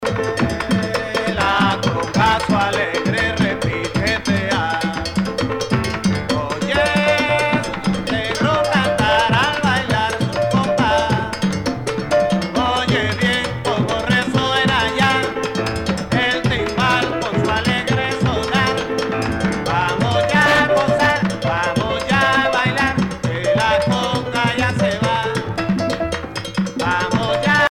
danse : conga
Pièce musicale éditée